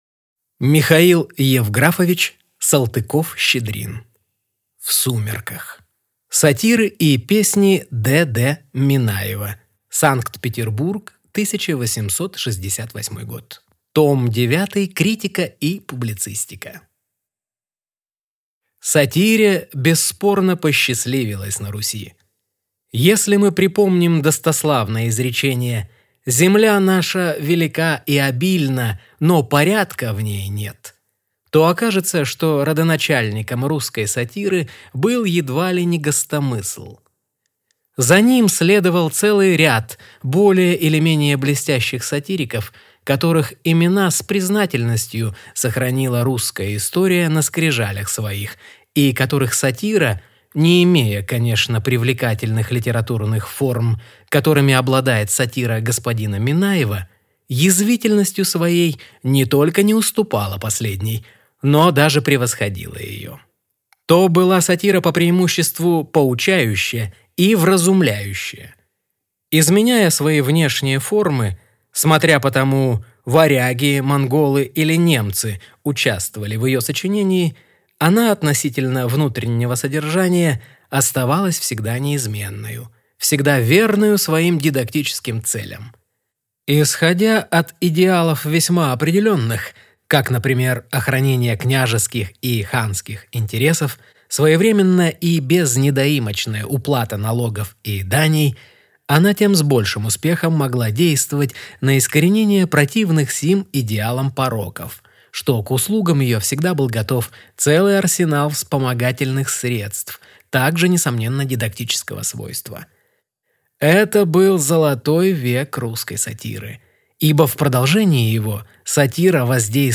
Аудиокнига В сумерках | Библиотека аудиокниг